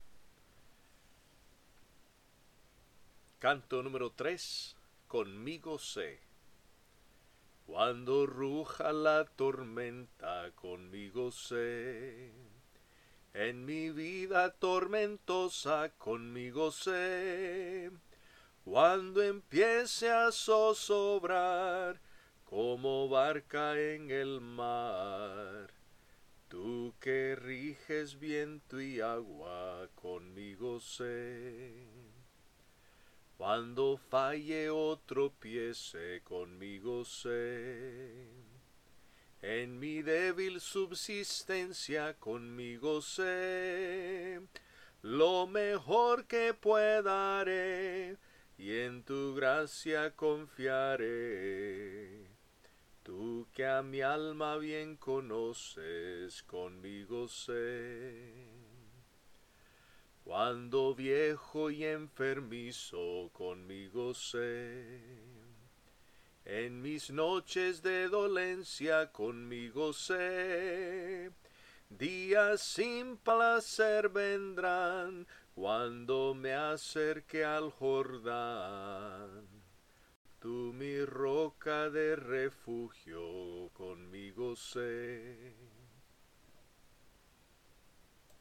Se ha optado por interpretar únicamente la melodía, prescindiendo de las voces de armonía (alto, tenor y bajo) con el propósito de facilitar el proceso de aprendizaje. Al centrarse exclusivamente en el soprano (la melodía), el oyente puede captar con mayor claridad las notas y matices sonoros.